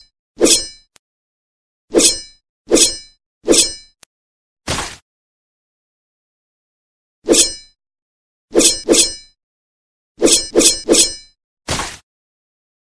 Suara Sabetan Pedang
Kategori: Suara senjata tempur
Suara ini memberikan sentuhan yang khas dan dramatis, sehingga video kita terasa lebih hidup.
suara-sabetan-pedang-id-www_tiengdong_com.mp3